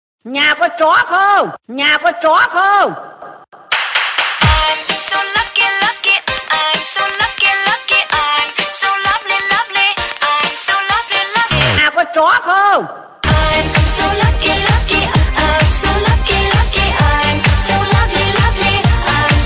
Nhạc Chuông Chế Hài Hước